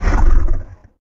Angry Chimera Growls
tb_growl_7.ogg